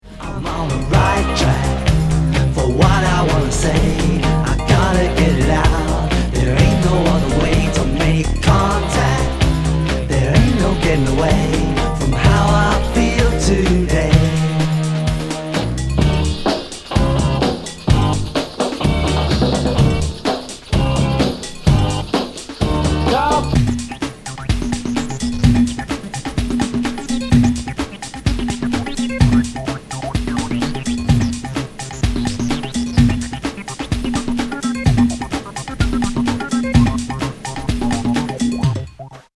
サンプリング・コラージュ・ダンス・ミュージック＆ロッキンな